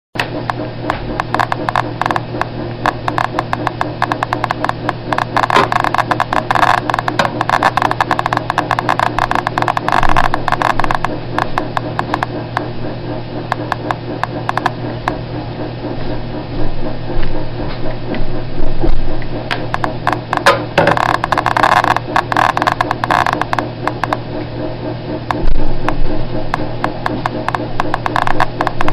Треск счётчика Гейгера (Geiger counter)
Отличного качества, без посторонних шумов.